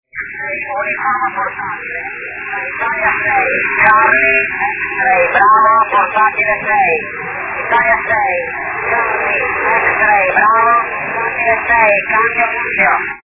Archivos sonido de QSOs en 10 GHz SSB
827 Kms Tropo Mar